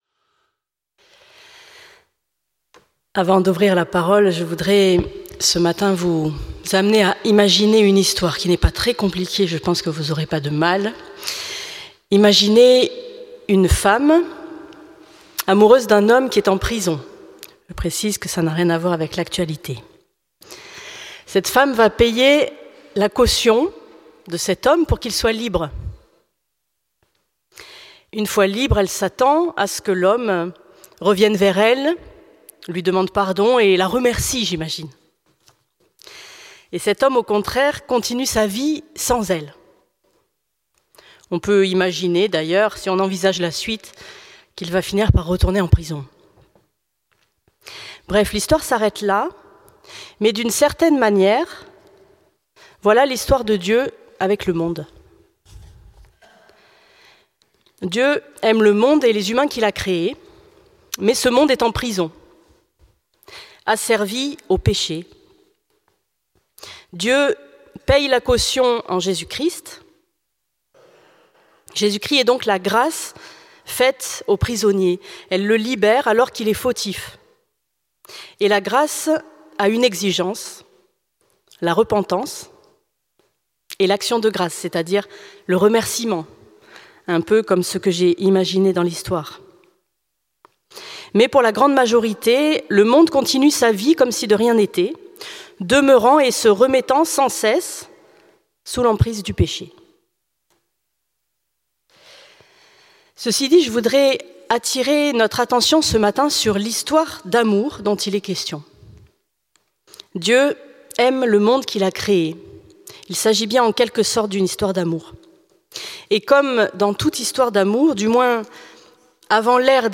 Prédication du 16 novembre 2025.